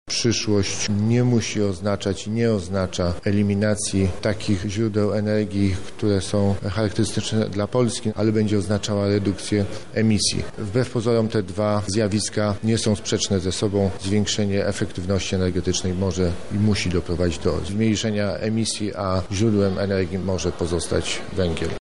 – Mówi dr Maciej Grabowski, Minister Środowiska.